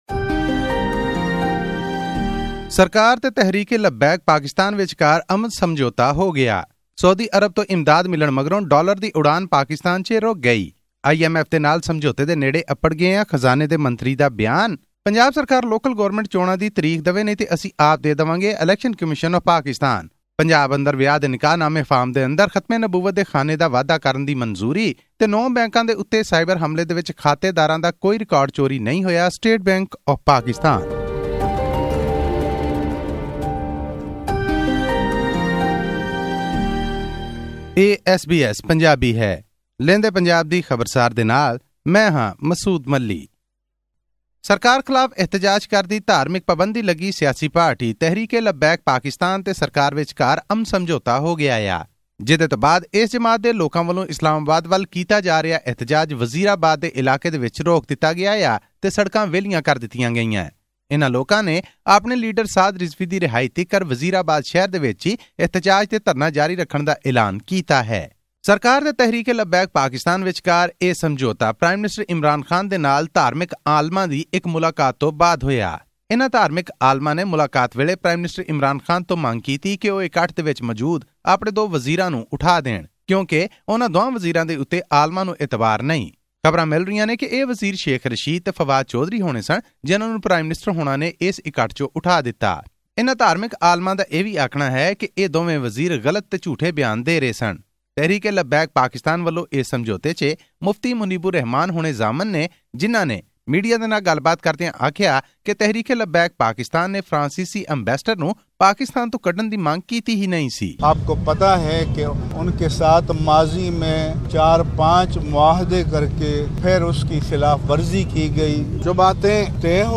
With the Saudi Arabia government agreeing to revive financial aid to Pakistan, Prime Minister Imran Khan thanked its ally for stepping up for Islamabad in its difficult times. This and more in our weekly news update from Pakistan.